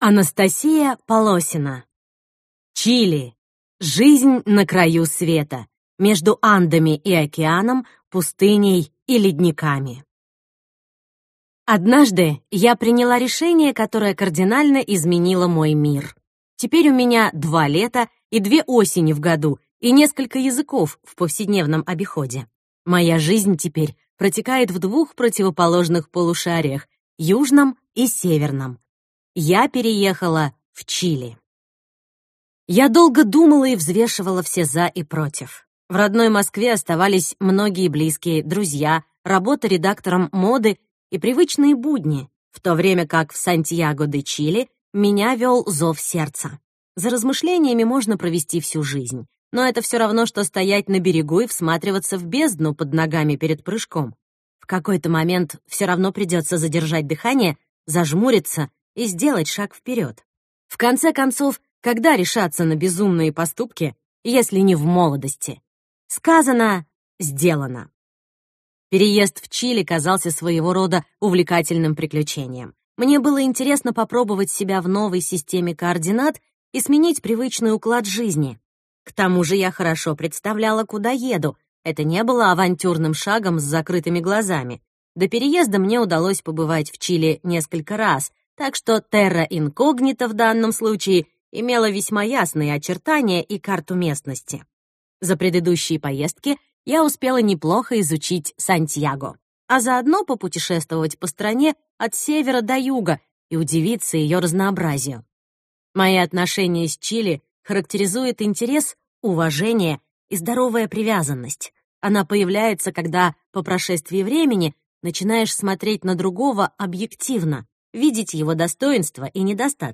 Аудиокнига Что такое Чили | Библиотека аудиокниг